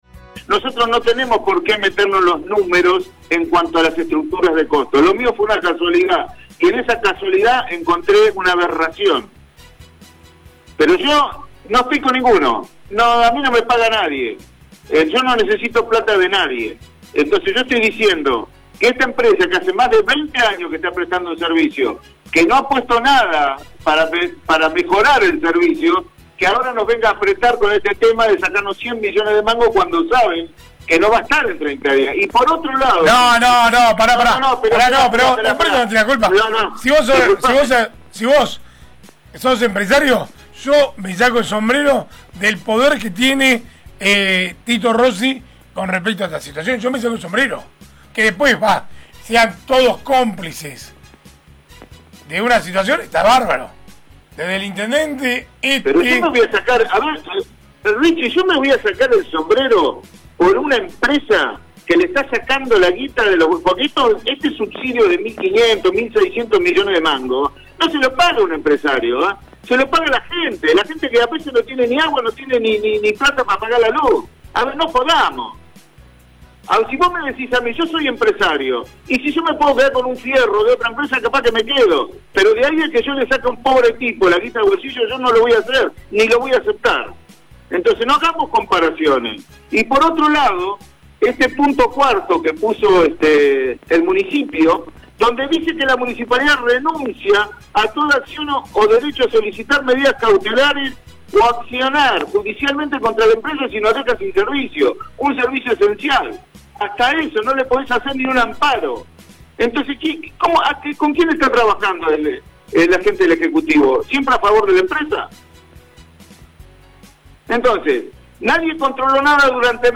Así lo confirmó en el aire de RADIOVISIÓN: